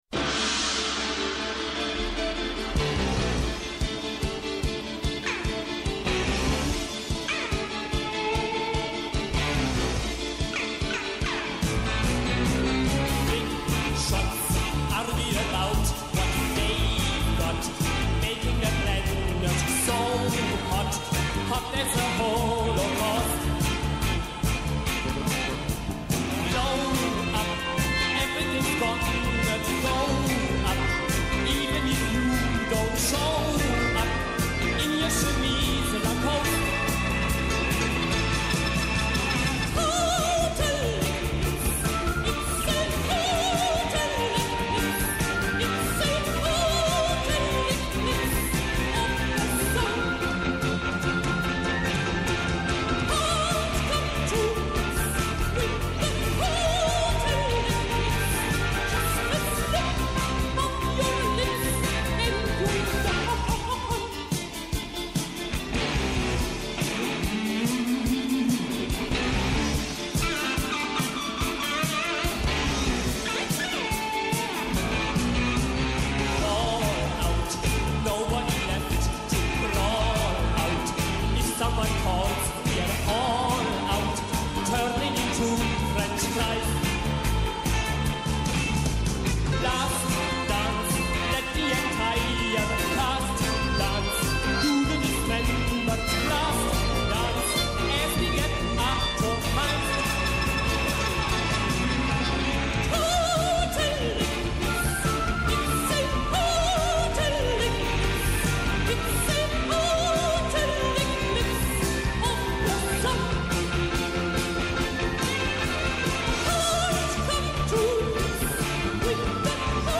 Καλεσμένοι σήμερα στην εκπομπή “Ναι μεν Αλλά”:
Από Δευτέρα έως Πέμπτη 11 με 12 το μεσημέρι στο Πρώτο Πρόγραμμα. ΠΡΩΤΟ ΠΡΟΓΡΑΜΜΑ Ναι μεν, Αλλα…